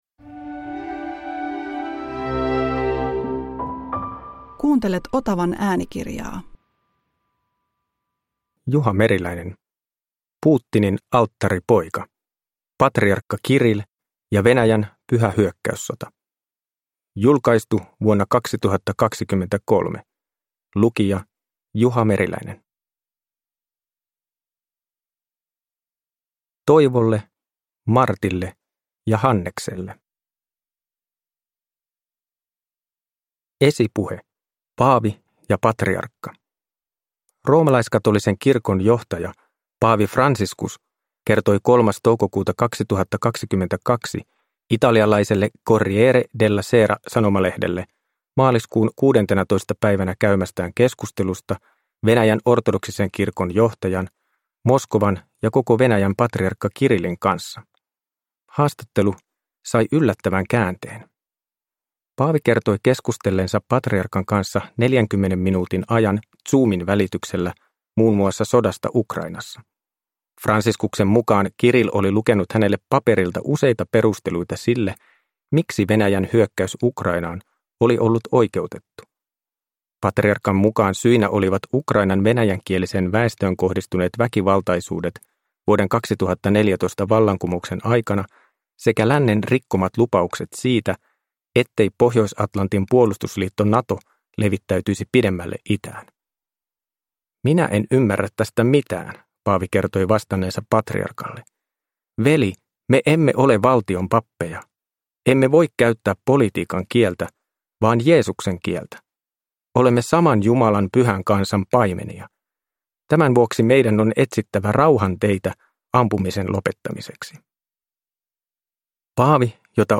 Putinin alttaripoika – Ljudbok – Laddas ner